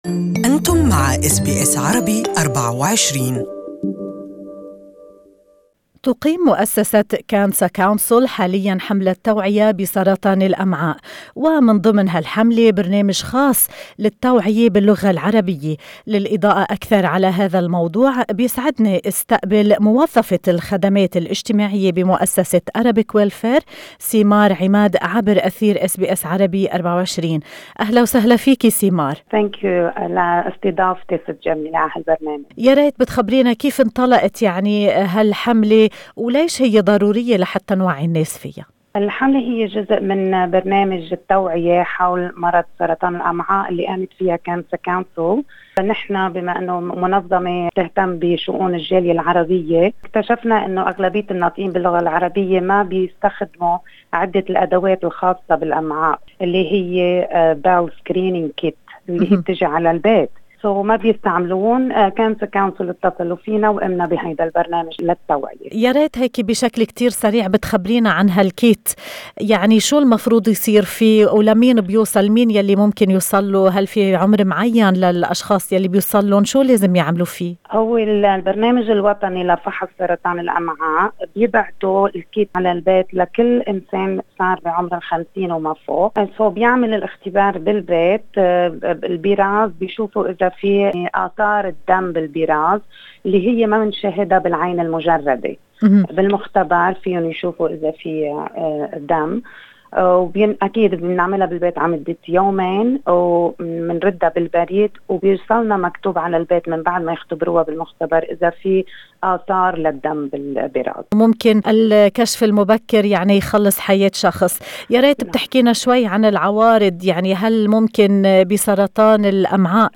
استمعوا الى المزيد في المقابلة الصوتية على الرابط المرفق بالصورة شارك